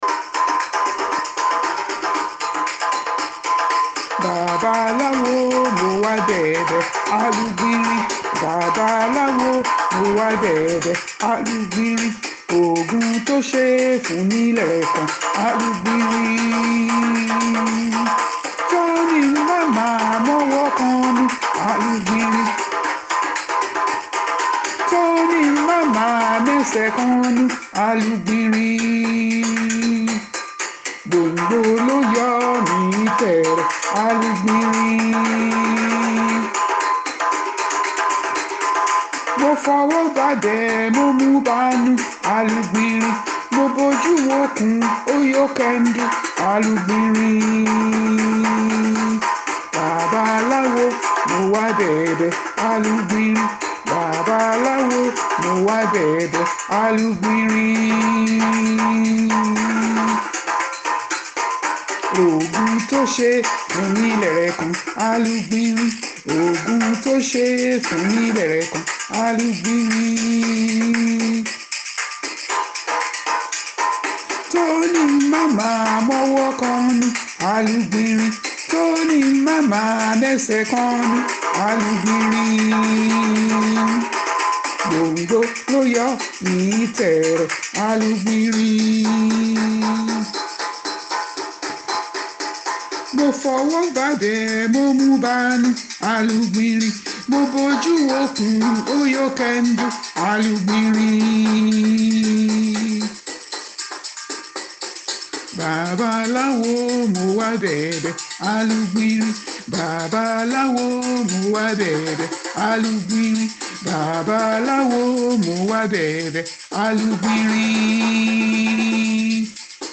Ijapa was singing, with tears in his eyes, sobbing noisily, heaving and panting as if about to die – a pitiful sight:
Story3song1.mp3